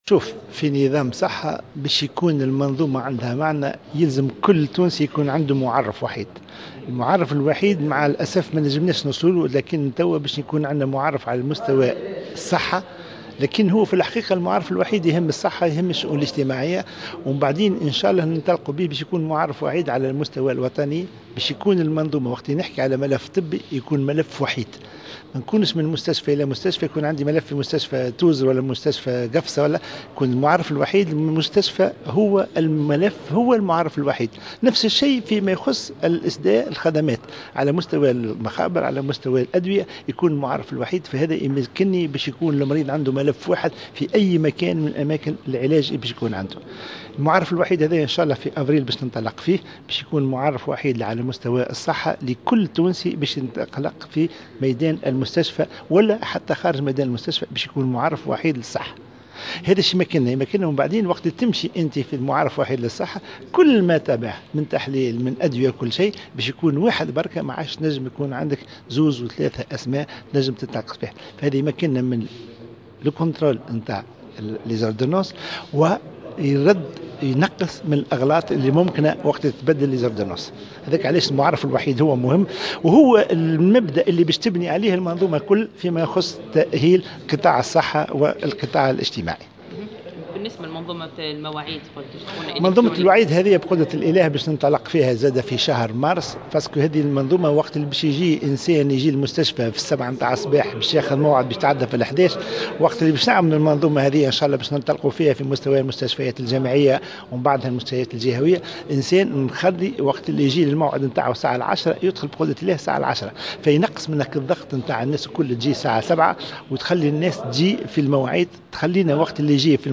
وأوضح في تصريح لمراسلة "الجوهرة أف أم" على هامش ندوة صحفية بقصر الحكومة بالقصبة، انه سيتم رقمنة بطاقة العلاج من خلال تركيز منظومة المعرف الوحيد لكل مريض يتمتع بخدمات الصحة بالمستشفيات العمومية.